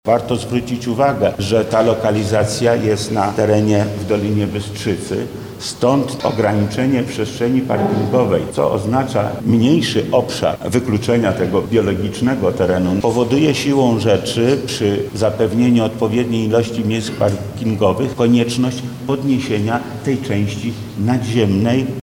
• mówi Lech Sprawka, wojewoda lubelski.